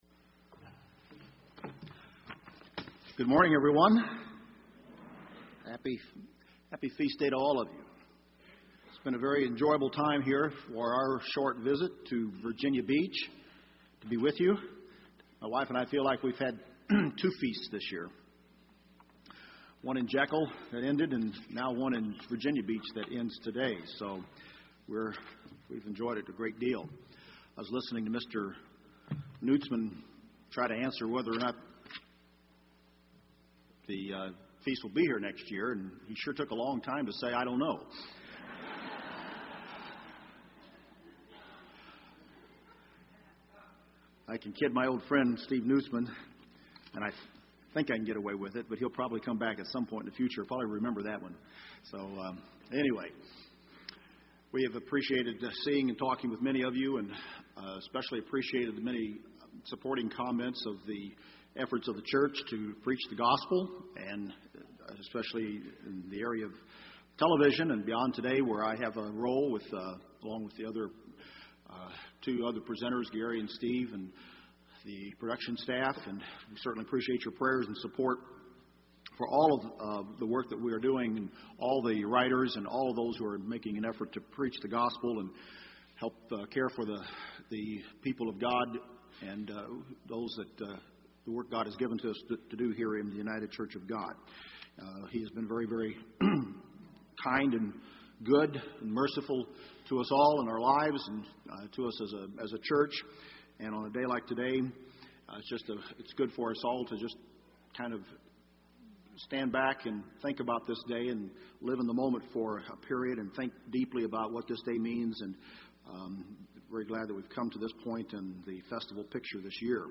This sermon was given at the Steamboat Springs, Colorado 2011 Feast site.